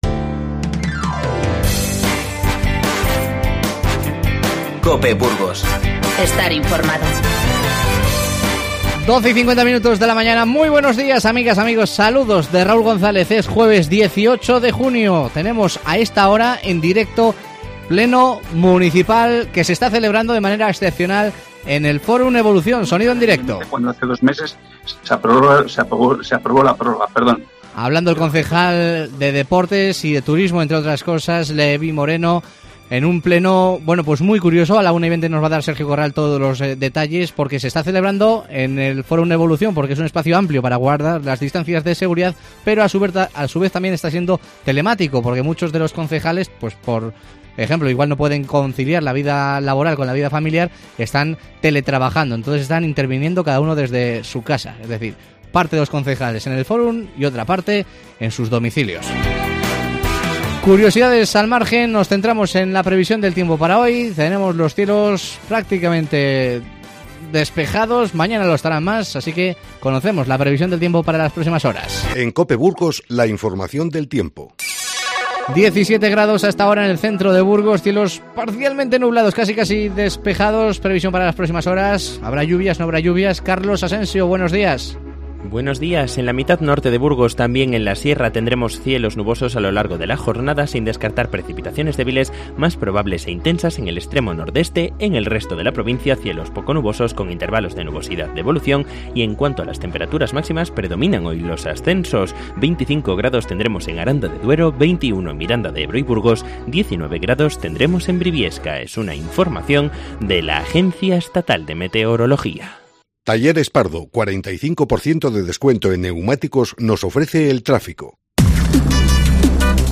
Conectamos en directo con el Fórum Evolución, donde se celebra de forma excepcional el pleno del Ayuntamiento de Burgos